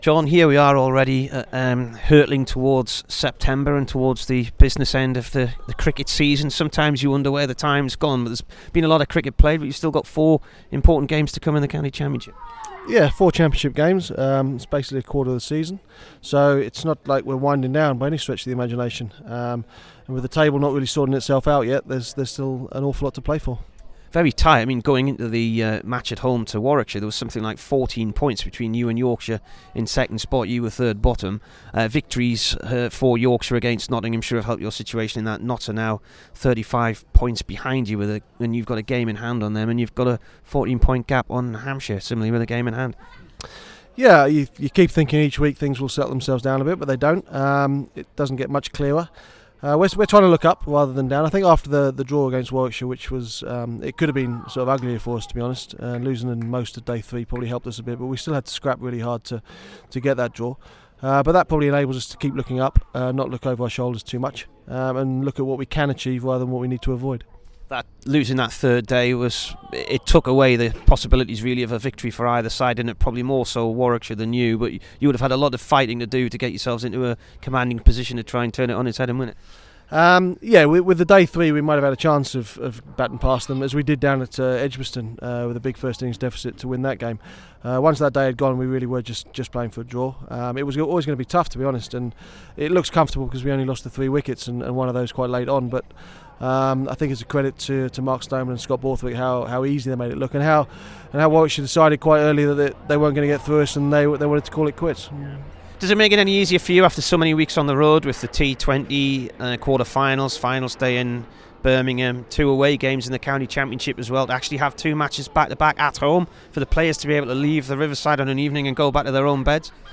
JON LEWIS INT
THE DURHAM COACH PREVIEWS NOTTS IN THE CHAMPIONSHIP